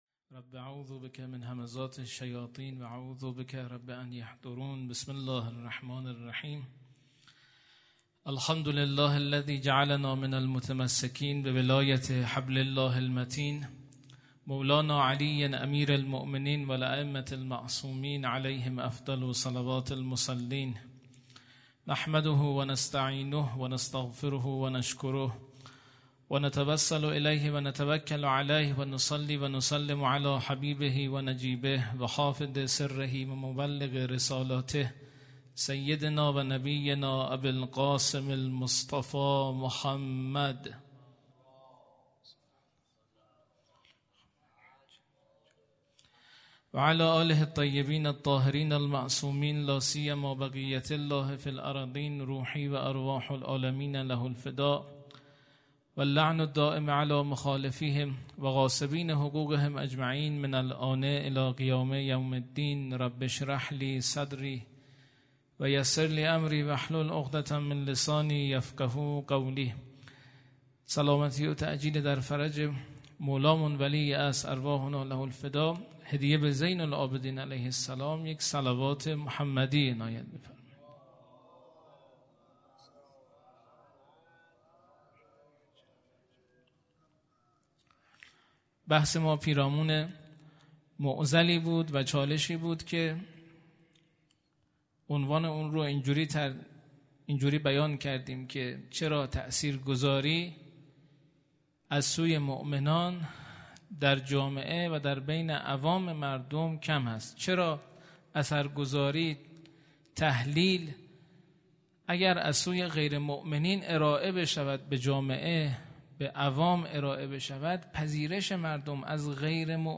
هیئت دانشجویی فاطمیون دانشگاه یزد